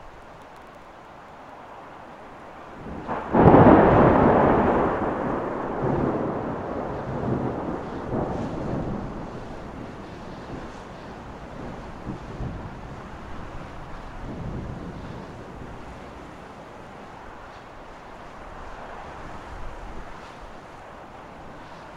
Thunder Storm » single thunder clap
描述：A single thunder clap and rain which is clean with slight distortion just over peak level. Recorded with a high quality mic direct to computer.
标签： fieldrecording lightning nature rain storm thunder thunderstorm weather
声道立体声